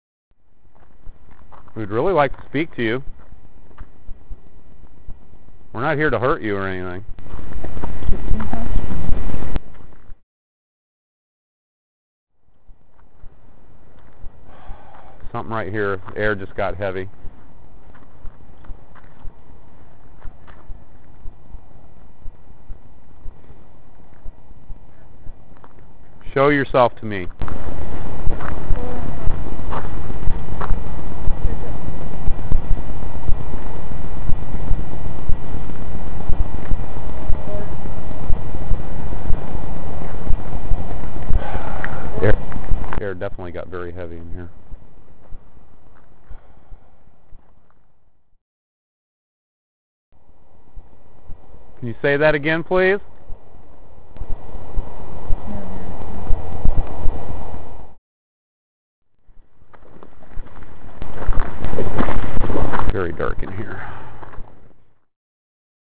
Audio Evidence: Although indiscernible and barley audible at the times they were recorded, multiple EVP were captured on a digital recorder during the investigation of Primrose Road.
All six recorded instances sound as though they are emanating from a separate entity.
An English accent is detectable in the womans voice.
This voice is distinct from the others, as it sounds almost electronic.
This voice is much lower in tone and is difficult to hear. Of all the EVP captured however, this voice is perhaps the most disturbing due to its dark, animal-like quality.